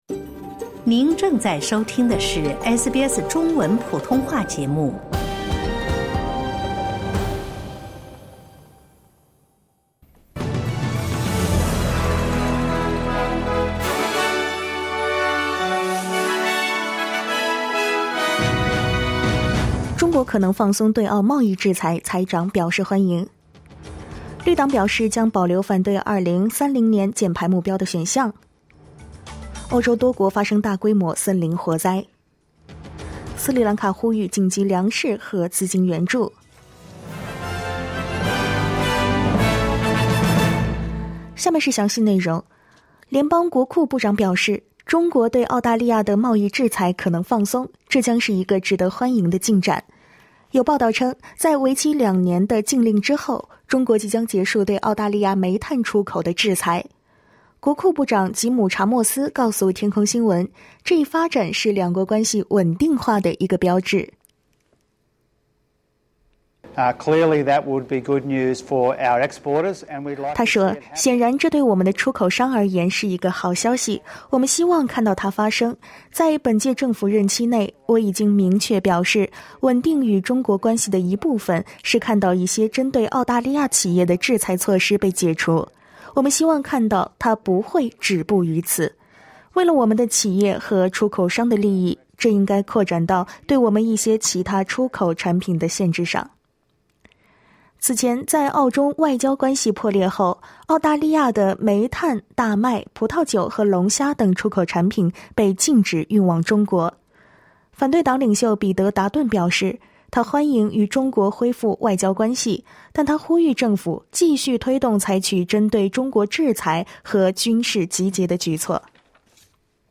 SBS早新闻（7月18日）